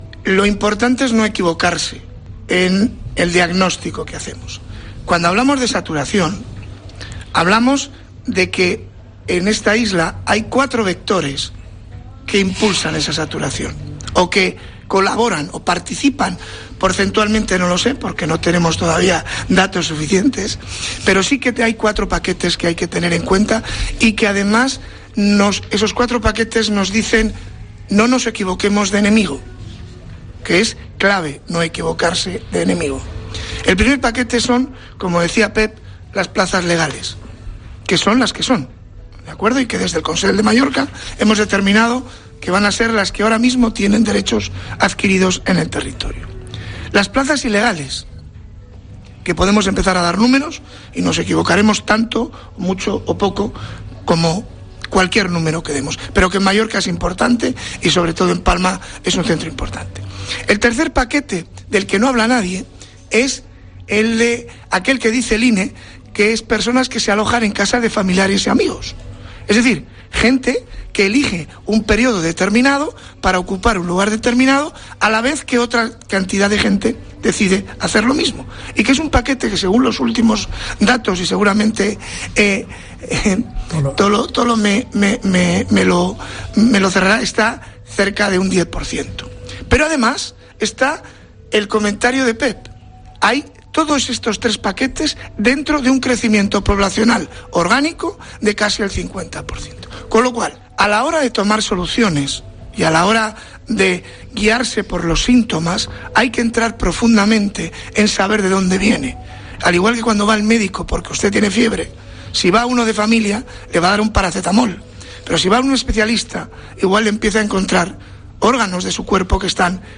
El conseller insular de Turismo, Marcial Rodríguez, en la Tertulia más Uno de COPE Baleares en el Hotel Es Príncep, ha puesto sobre la mesa una cuestión que es muy importante.